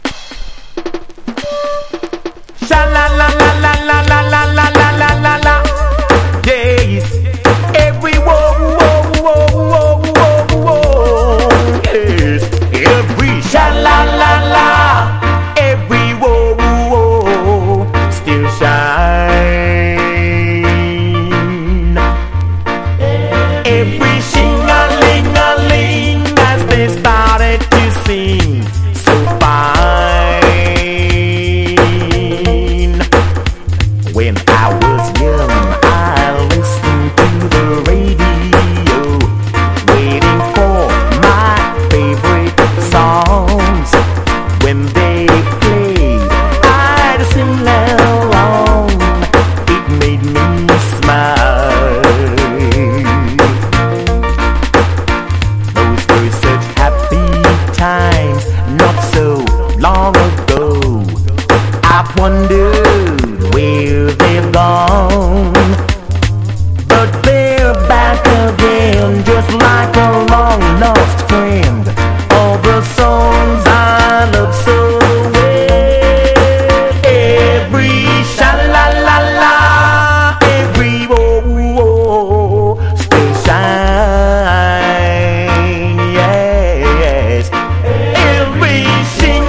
• REGGAE-SKA
所によりノイズありますが、リスニング用としては問題く、中古盤として標準的なコンディション。